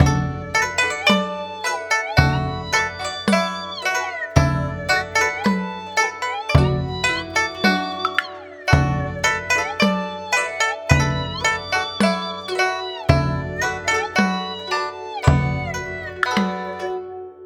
Lesson 7: Creating World Music
lesson-7-example-world.wav